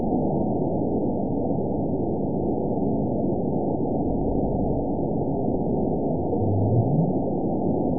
event 920834 date 04/11/24 time 00:30:09 GMT (1 year, 1 month ago) score 9.43 location TSS-AB02 detected by nrw target species NRW annotations +NRW Spectrogram: Frequency (kHz) vs. Time (s) audio not available .wav